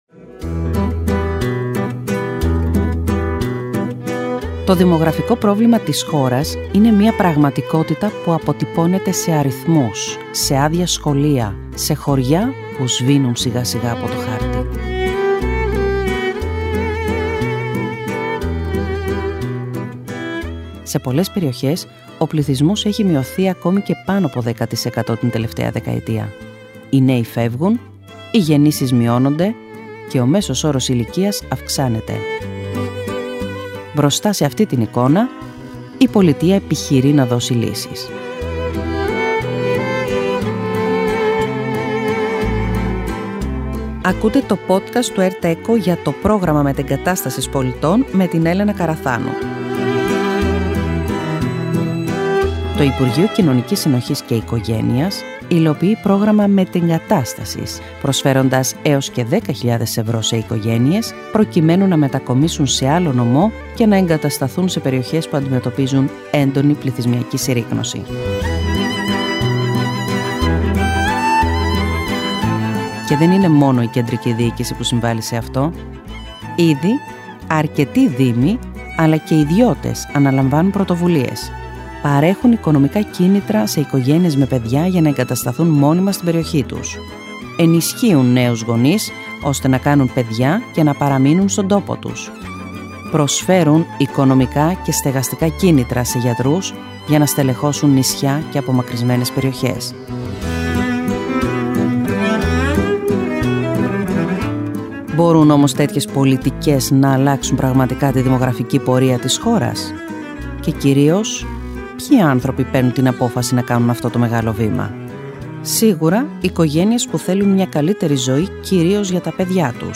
Δήμαρχος Σικίνου
Συνοχής & Οικογένειας με κίνητρο το ποσό των 10.000 ευρώ σε κάθε οικογένεια ή πολίτη και Δόμνα Μιχαηλίδου , Υπουργός Κοινωνικής Συνοχής και Οικογένειας.